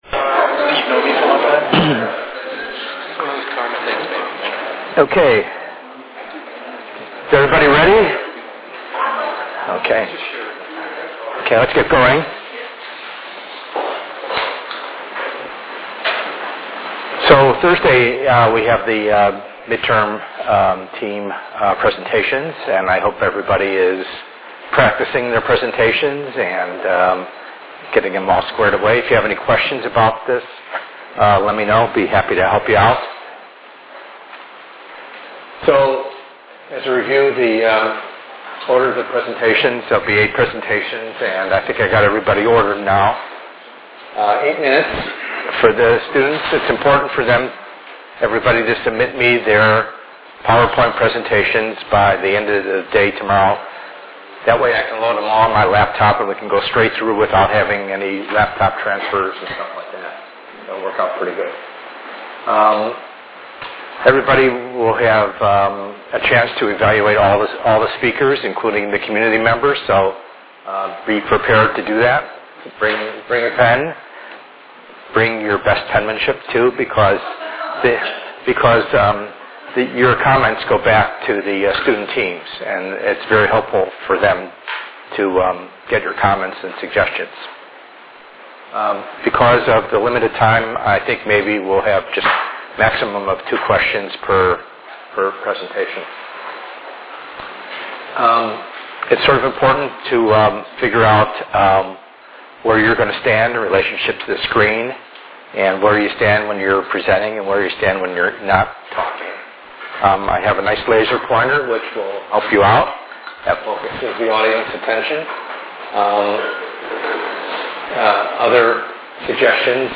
ENGR110/210: Perspectives in Assistive Technology - Lecture 06a